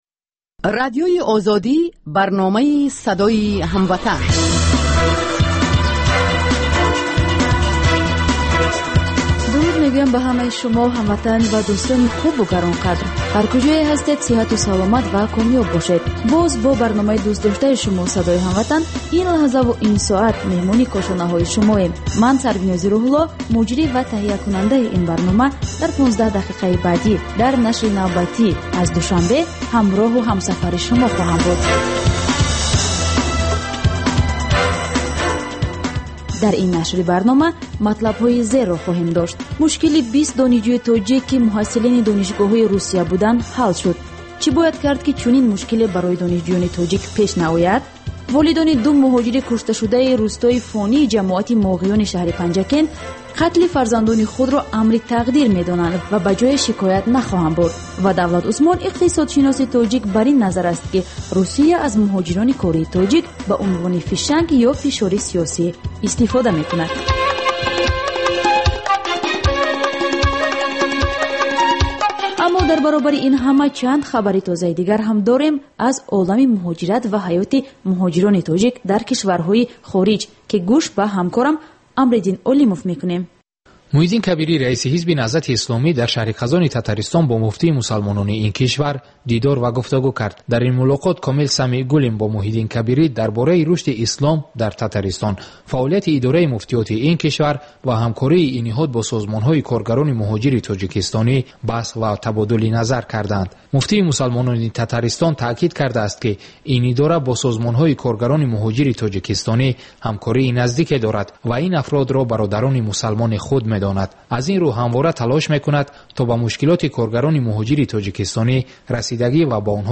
Тоҷикон дар кишварҳои дигар чӣ гуна зиндагӣ мекунанд, намунаҳои комёб ва нобарори муҳоҷирон дар мамолики дигар, мусоҳиба бо одамони наҷиб.